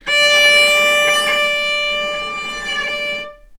healing-soundscapes/Sound Banks/HSS_OP_Pack/Strings/cello/sul-ponticello/vc_sp-D5-ff.AIF at bf8b0d83acd083cad68aa8590bc4568aa0baec05
vc_sp-D5-ff.AIF